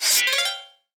sfx_pickup_ninja.wav